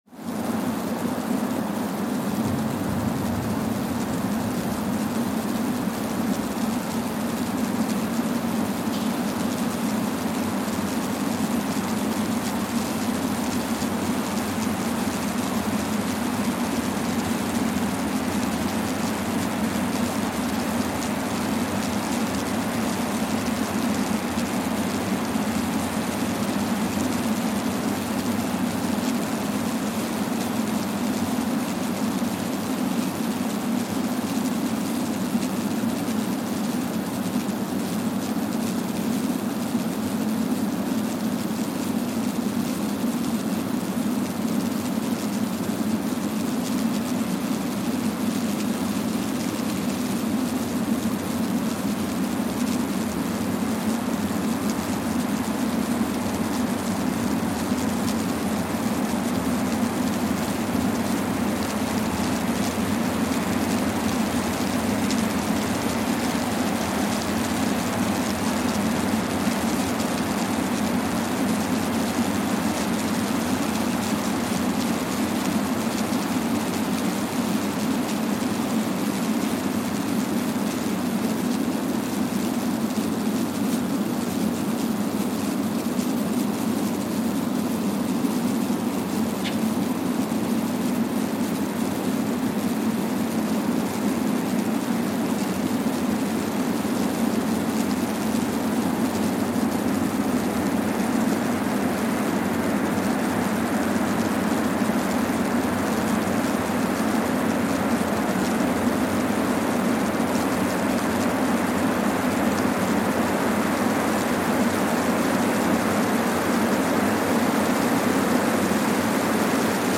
Kwajalein Atoll, Marshall Islands (seismic) archived on July 22, 2023
Sensor : Streckeisen STS-5A Seismometer
Recorder : Quanterra Q330-HR @ 40 Hz
Speedup : ×1,000 (transposed up about 10 octaves)
Loop duration (audio) : 05:45 (stereo)
SoX post-processing : highpass -2 90 highpass -2 90